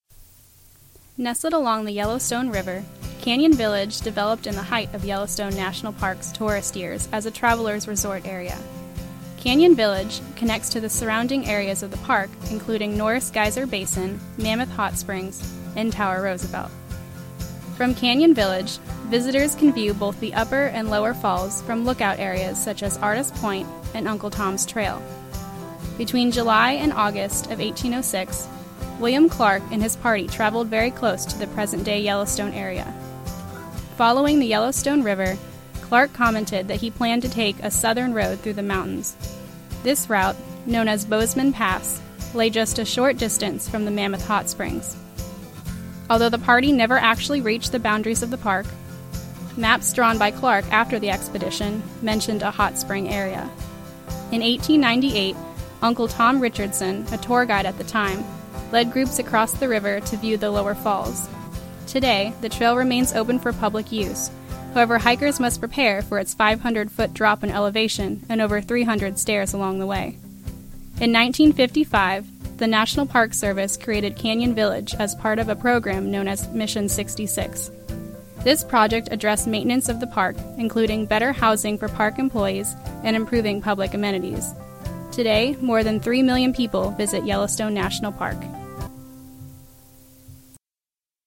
Researched, written, and narrated by University of West Florida Public History Student